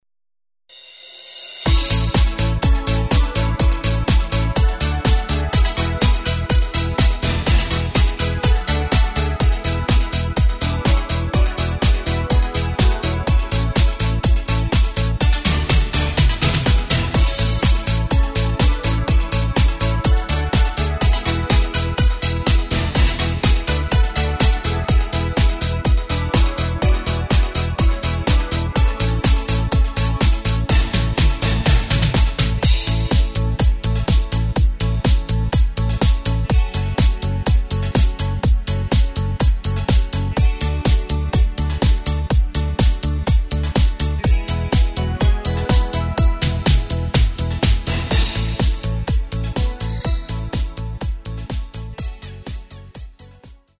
Takt:          4/4
Tempo:         124.00
Tonart:            A
Discofox aus dem Jahr 2015!